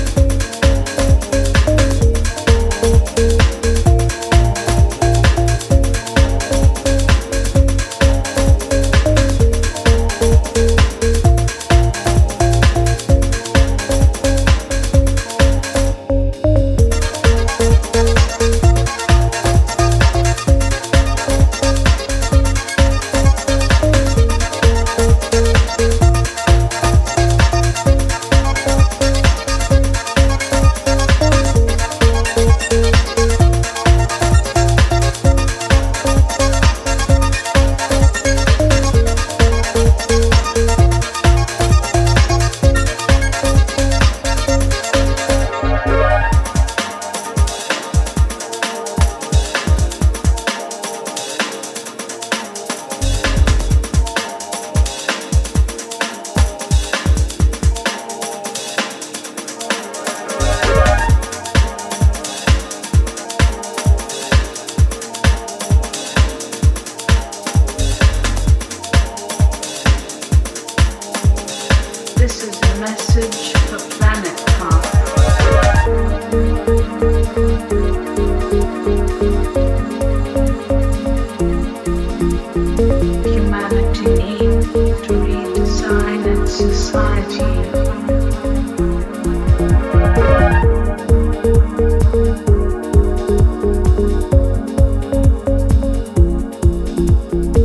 Dub, house, breaks, acid, ambient, progressive… you name it.